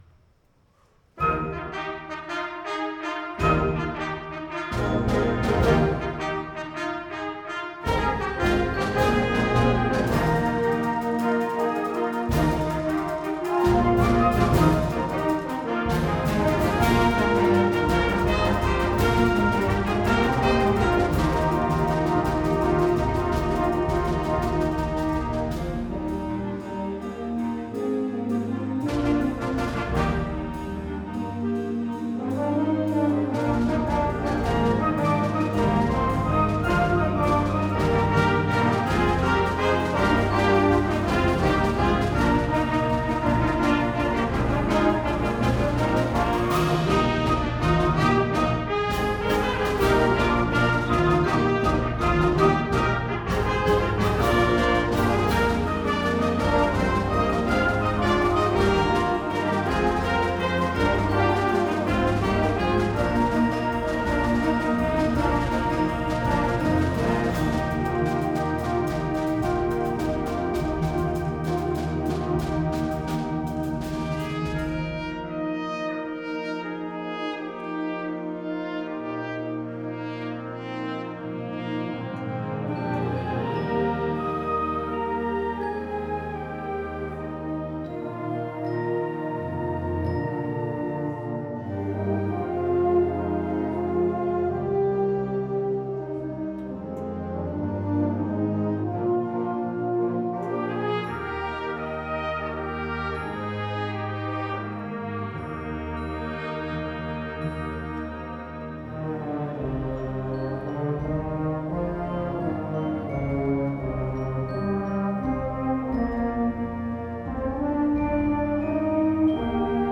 Liberty HS Symphonic Band @ Centennial HS